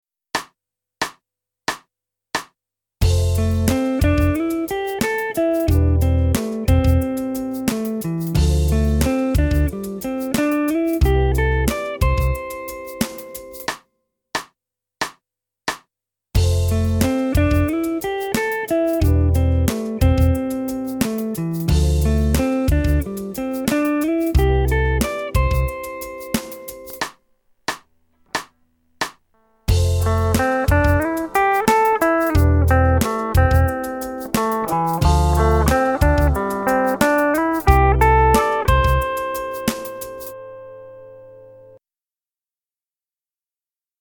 上で書いたことをポイントに、今回の課題譜にチャレンジしました☆